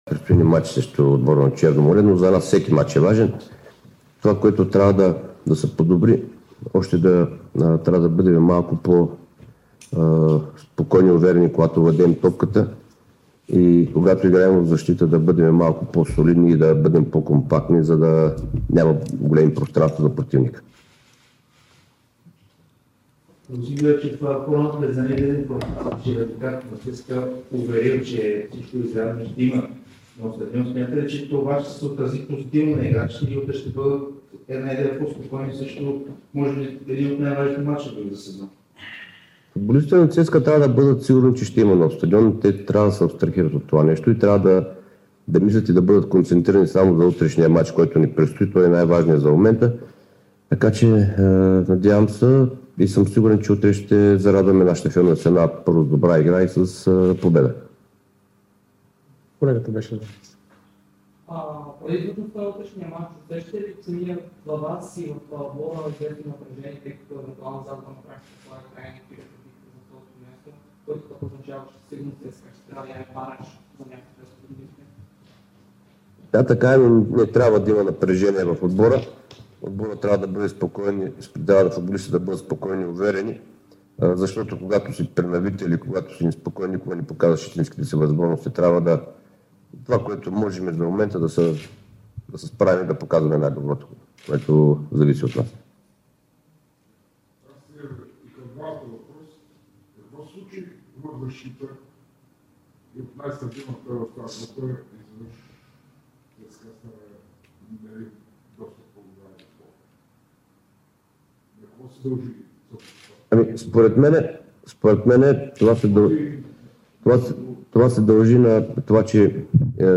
Старши треньорът на ЦСКА Стамен Белчев даде пресконференция преди домакинството срещу Черно море в efbet Лига.